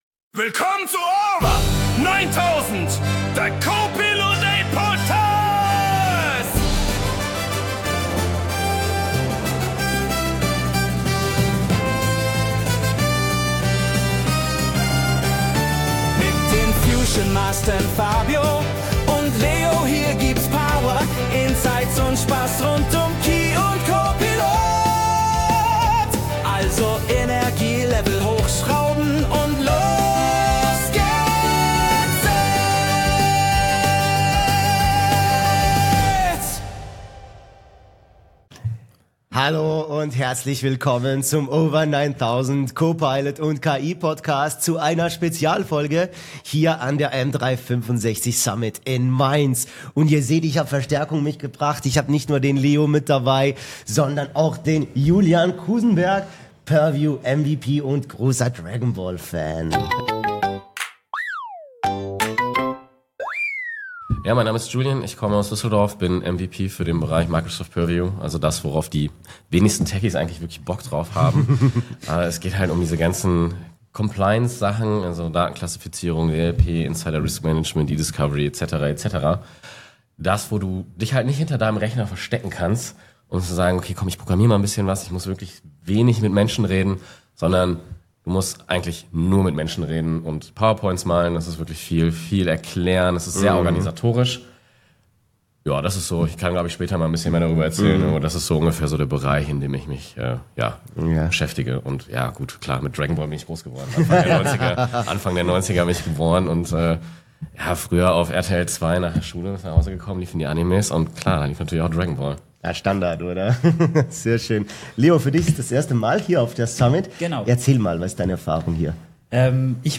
Diesmal direkt von der M365 Summit in Mainz, live aus dem exklusiven Podcast-Raum mit Top-Equipment .